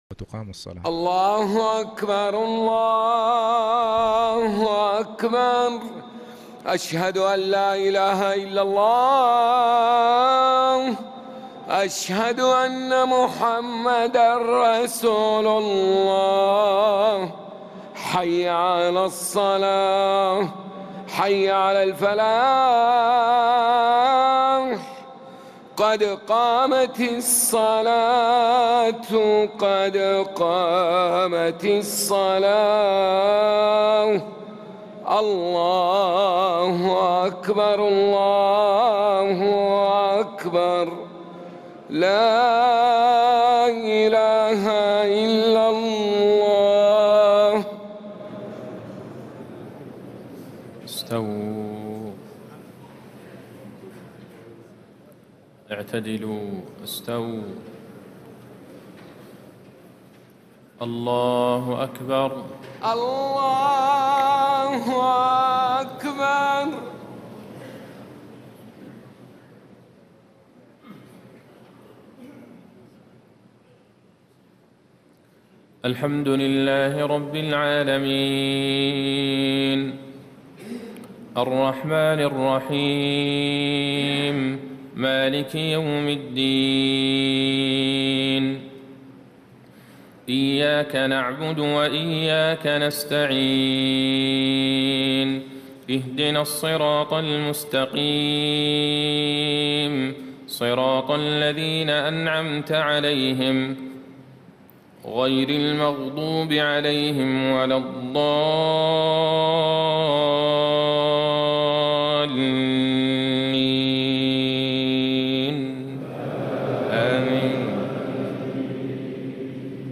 صلاة المغرب1 شعبان 1437هـ فواتح سورة المؤمنون 1-16 > 1437 🕌 > الفروض - تلاوات الحرمين